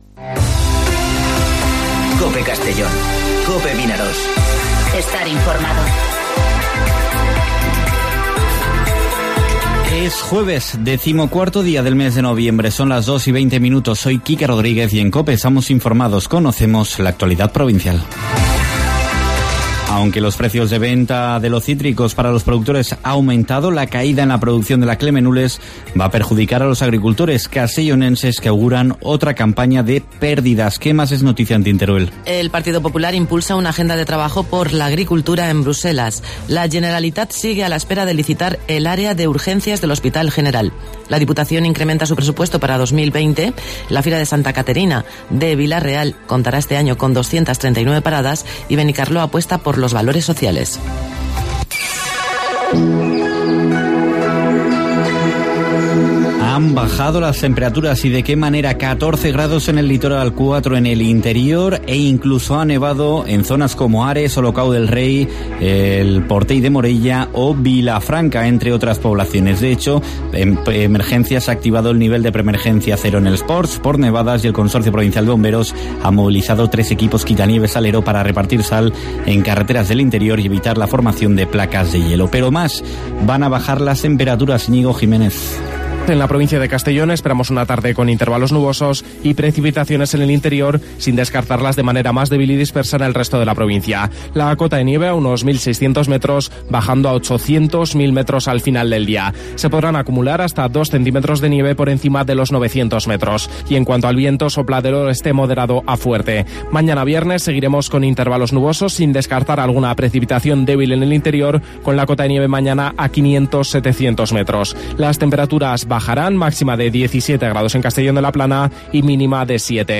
Informativo Mediodía COPE en Castellón (14/11/2019)